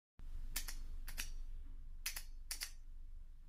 But that’s not enough, to better balance the long, metal body, a heavy block of chromed stainless steel was inserted and served as the end tip.
Hitting a block of metal: the LAMY econ